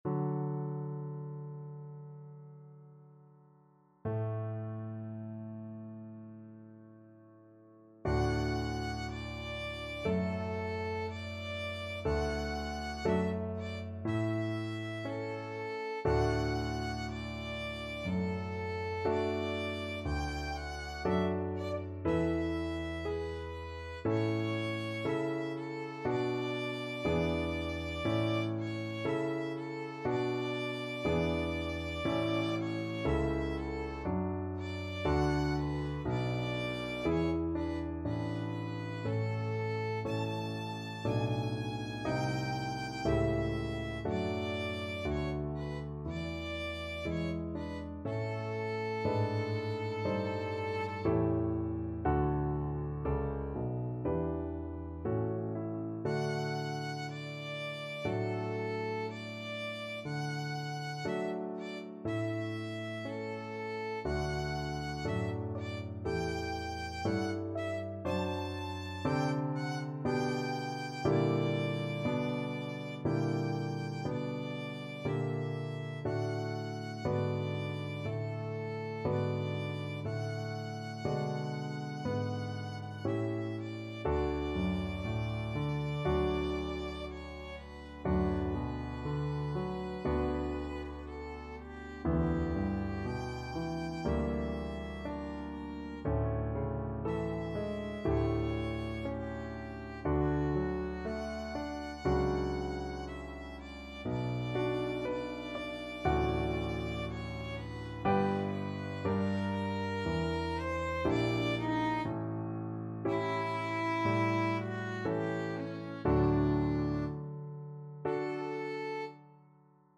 Violin
D major (Sounding Pitch) (View more D major Music for Violin )
~ = 100 Adagio =c.60
2/4 (View more 2/4 Music)
Classical (View more Classical Violin Music)
brahms_violin_concerto_2nd_VLN.mp3